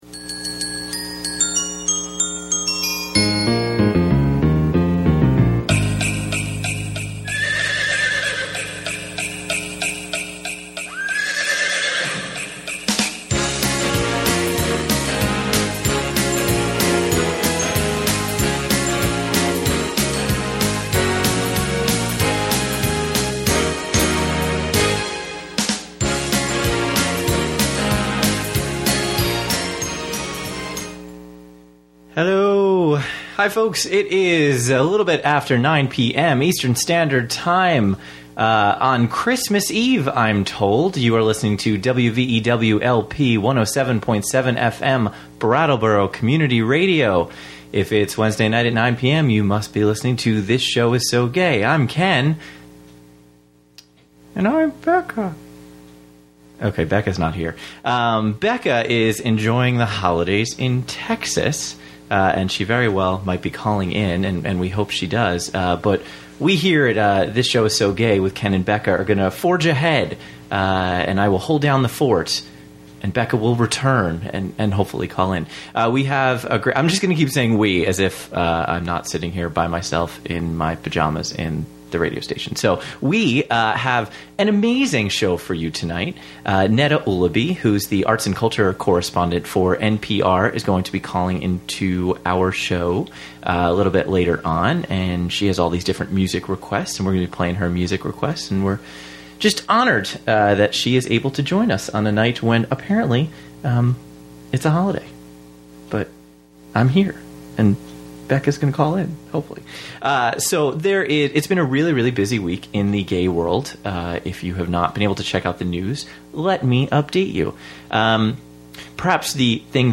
Her music truly lit up the switchboard...stunning, stunning voice.